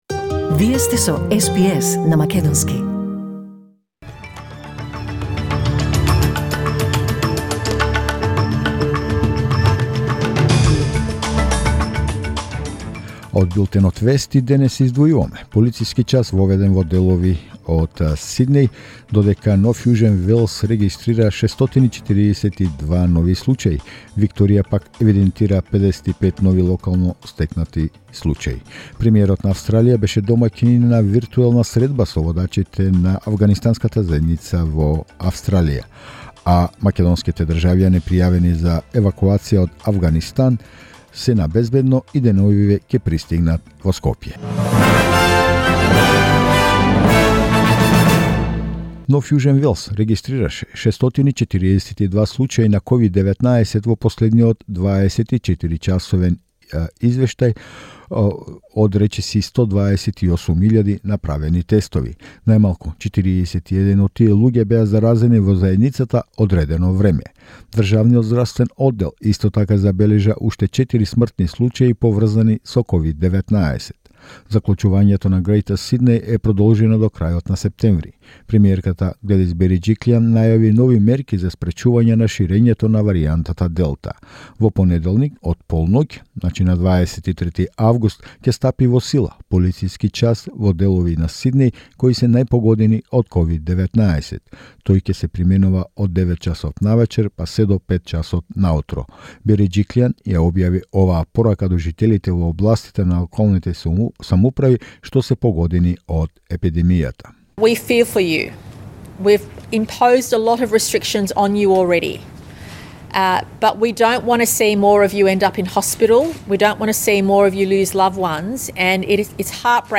SBS News in Macedonian 20 August 2021